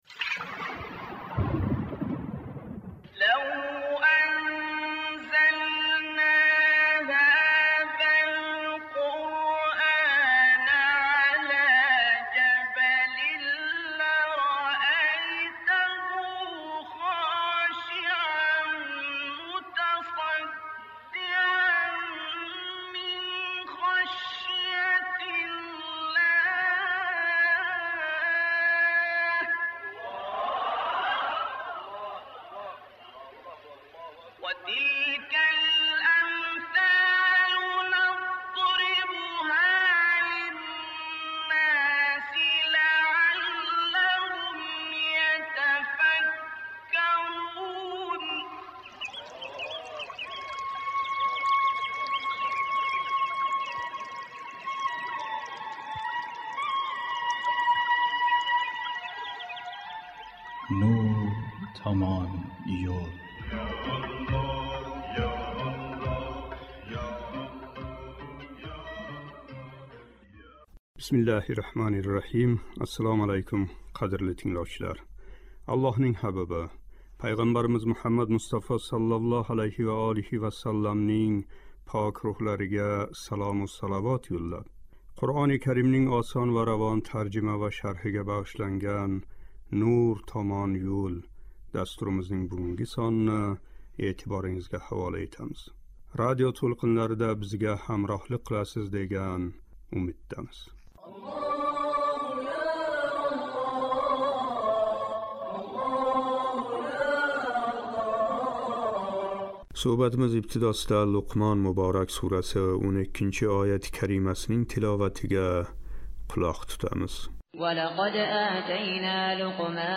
" Луқмон " муборак сураси 12-13-ояти карималарининг шарҳи. Суҳбатимиз ибтидосида «Луқмон " муборак сураси 12-ояти каримасининг тиловатига қулоқ тутамиз :